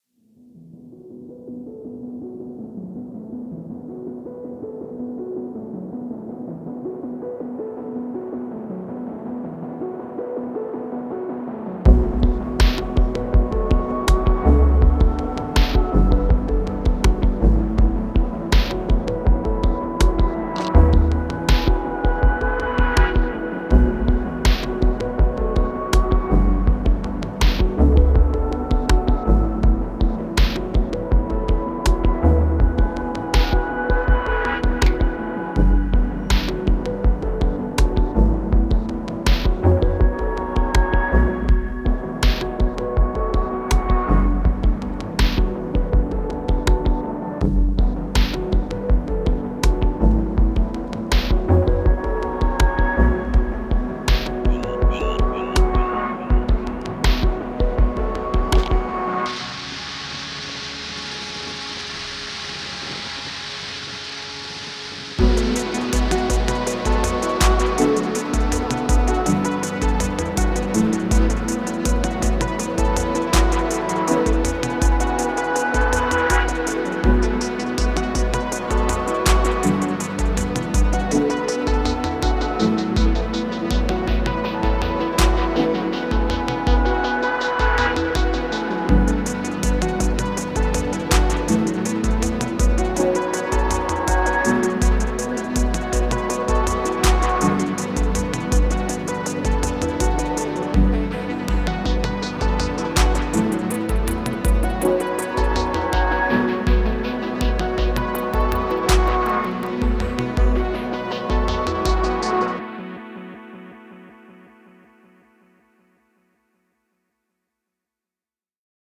Cinematic Electronica
Cinematic, mellow downtempo beat with playful synth line.